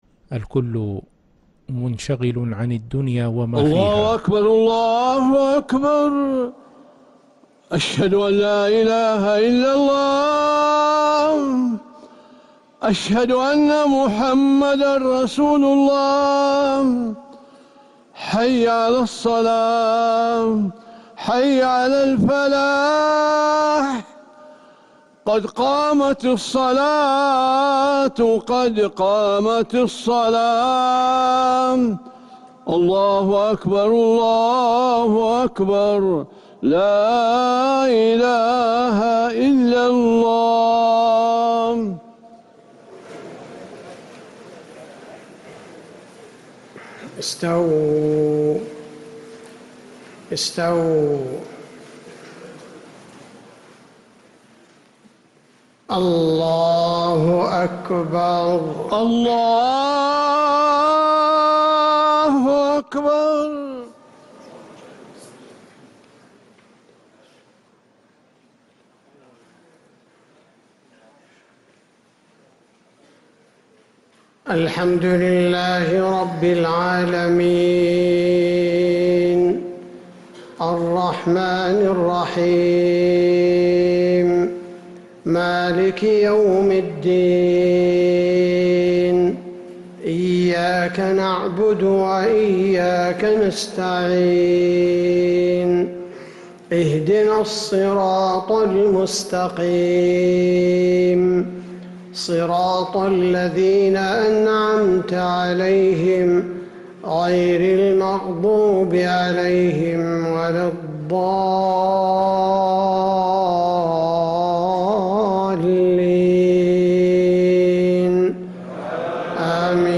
Madeenah Maghrib - 12th April 2026